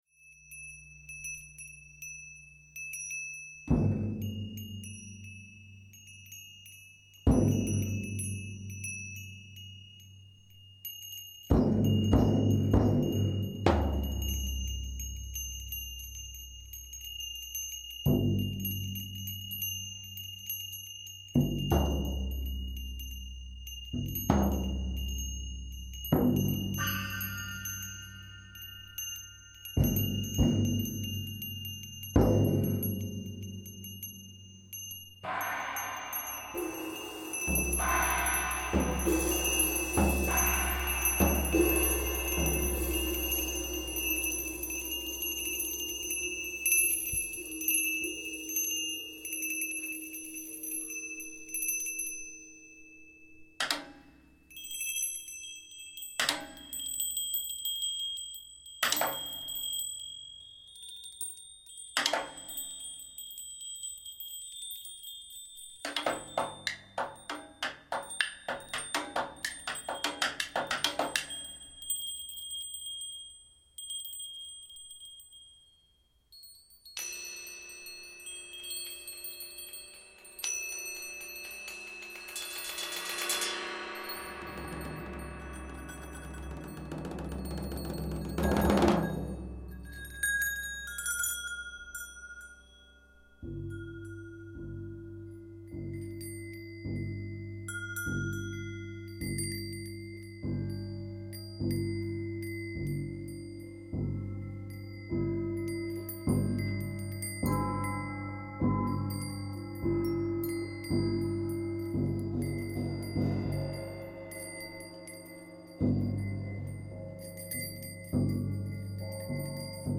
Concert / Performance
percussions